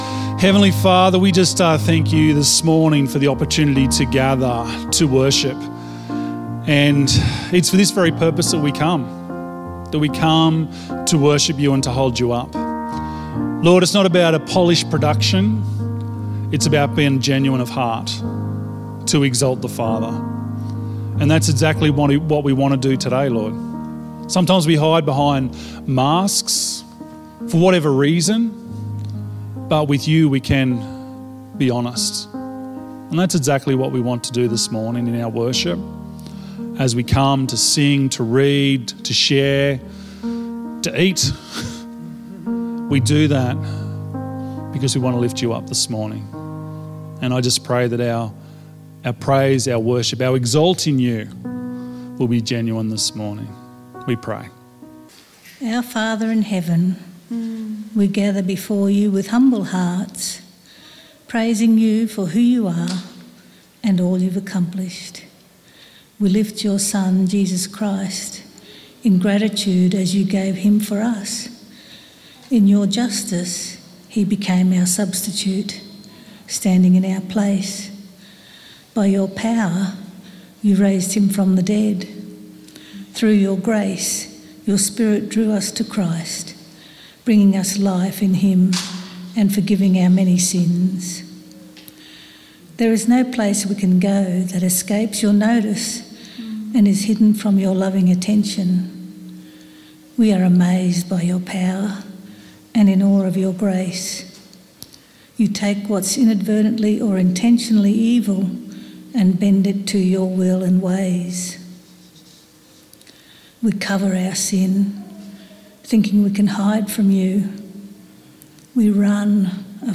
Sunday Sermons HOLY SPIRIT POWER